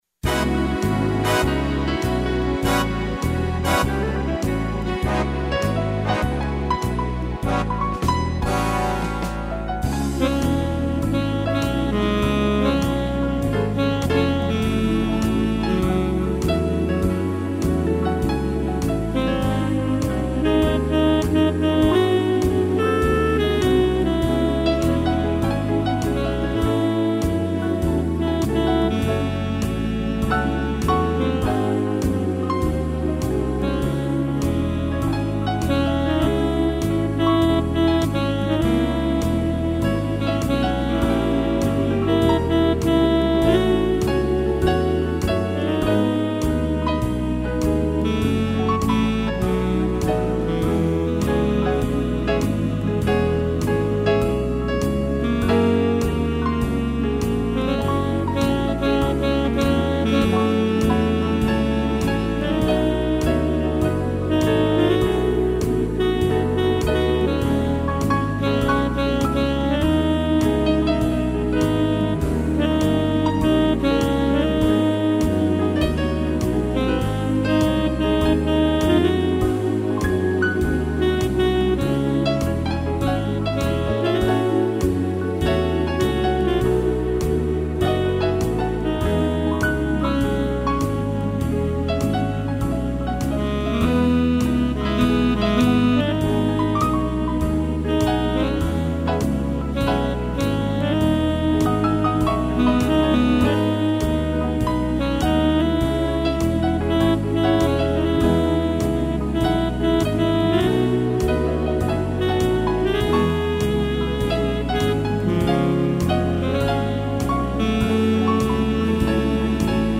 piano
instrumental